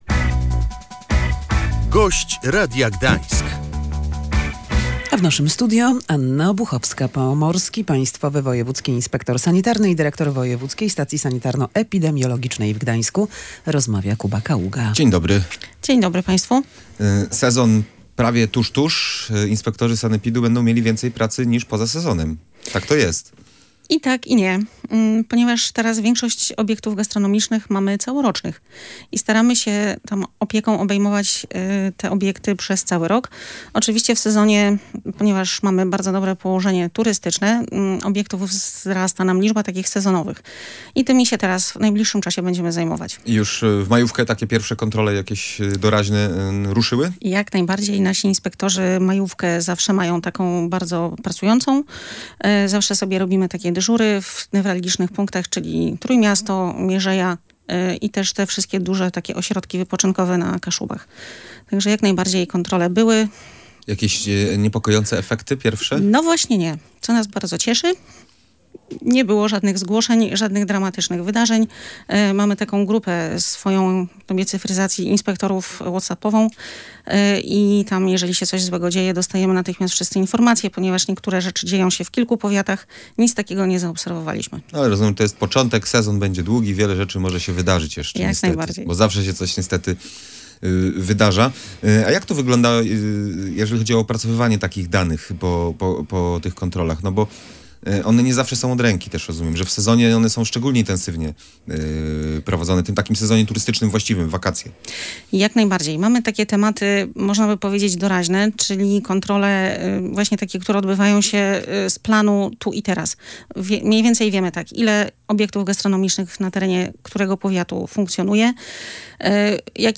Poprawia się jakość tłuszczów, ale pogarsza jakość lodów w sezonowych punktach gastronomicznych w województwie pomorskim – mówiła w Radiu Gdańsk Anna Obuchowska, Pomorski Państwowy Wojewódzki Inspektor Sanitarny i dyrektor Wojewódzkiej Stacji Sanitarno-Epidemiologicznej w Gdańsku.